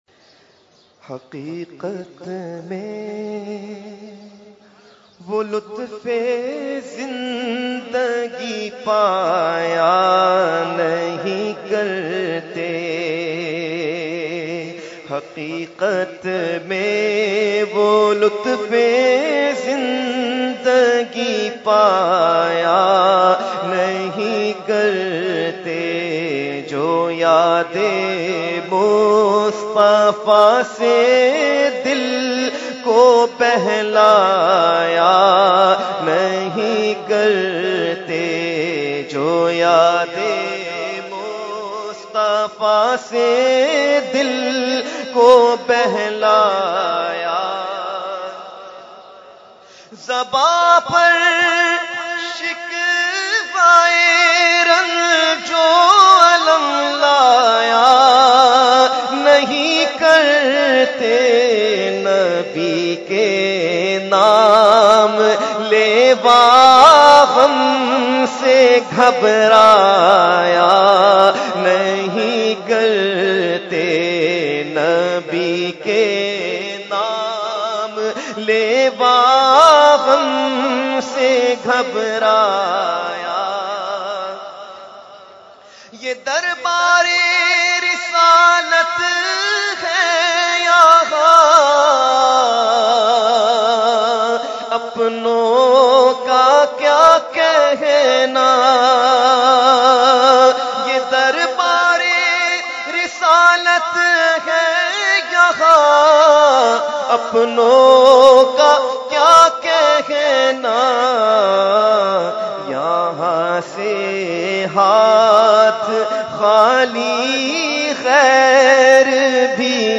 Category : Naat | Language : UrduEvent : Urs Qutbe Rabbani 2016